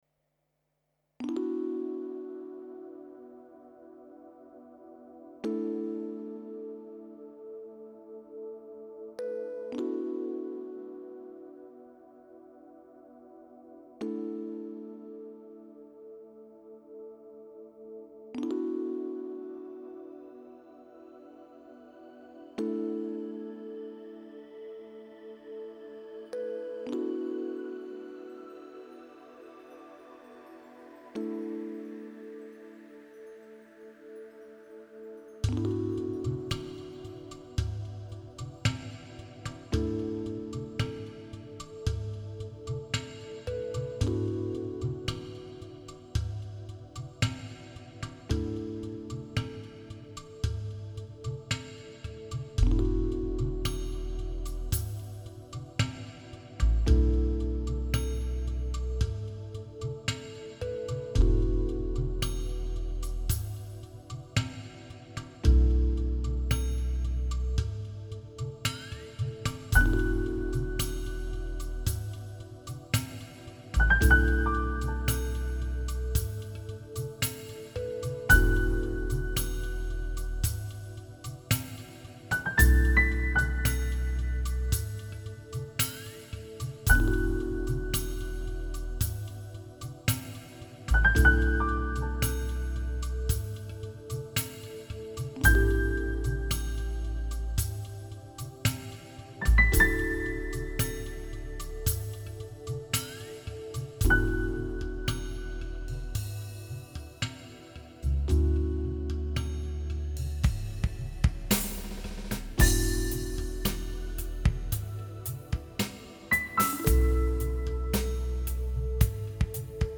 Download Song - Chill